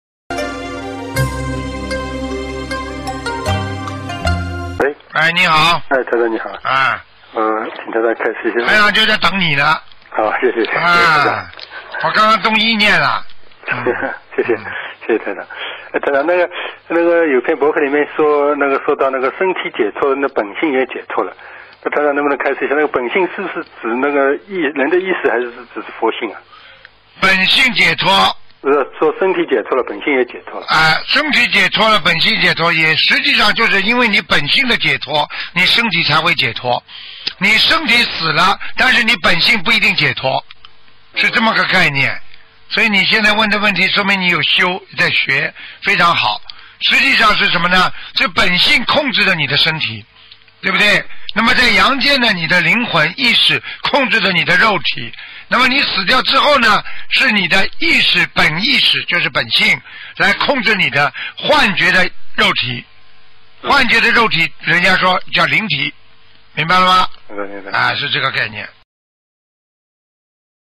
音频：有关本性的问题！问答2014年8月31日！58分08秒！